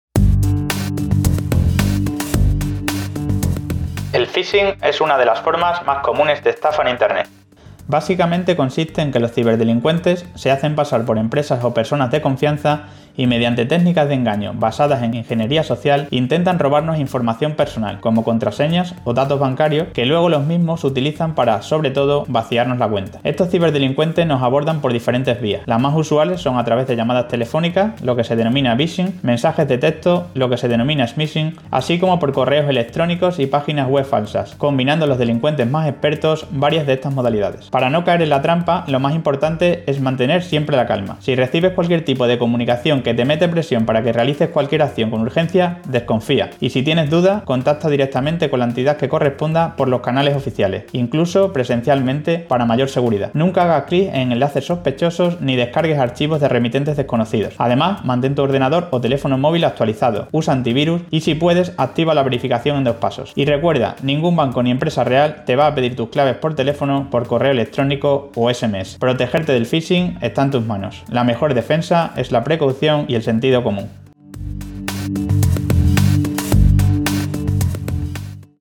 • Agentes expertos explican en audios de un minuto técnicas como el skimming digital y la "estafa de los likes" a través de redes sociales y plataformas digitales
La Policía Nacional ha activado una campaña preventiva contra ciberfraudes mediante micropodcast semanales narrados por agentes de su Unidad Central de Ciberdelincuencia (UCC).